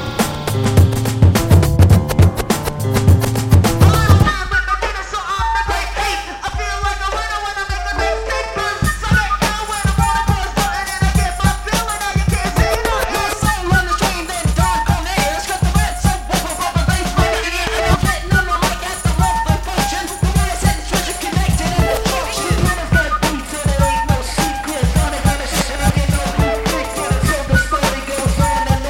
Flute Ringtones